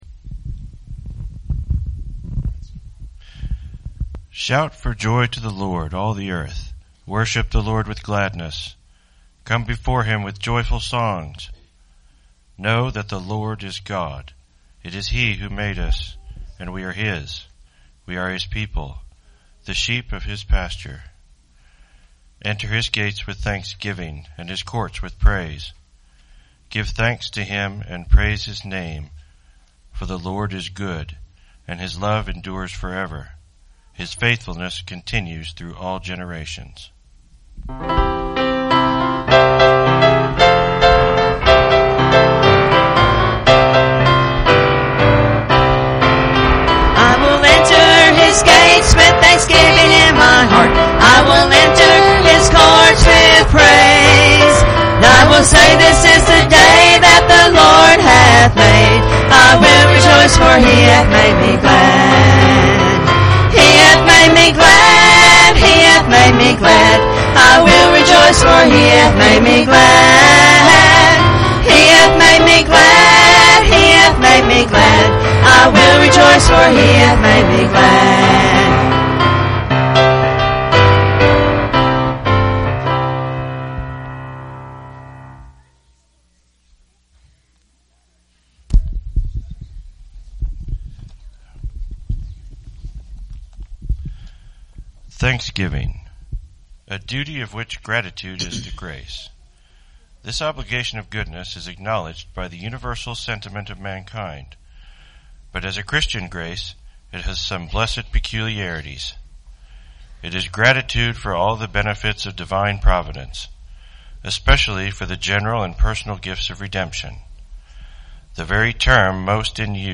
Thanksgiving Service